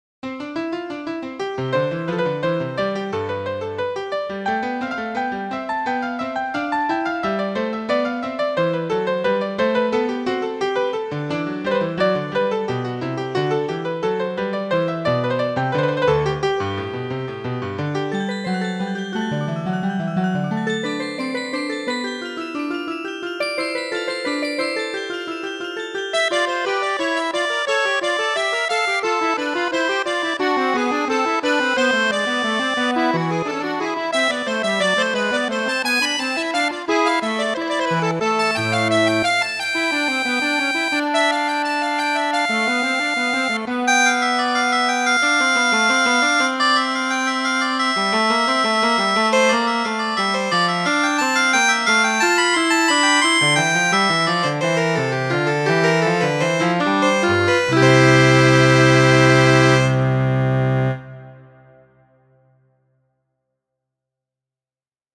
The above MIDI file is Invention no.1 by J.S.Bach. This piece was originally written for keyboard in the key of C major. This arrangement (unlike the original) changes orchestration throughout the composition.